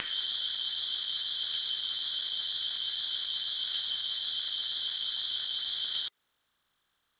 Il ya huit samples, qui constituent la banque de sons avec laquelle j'ai composé les quatres exemples de mixage disponibles sur cette page .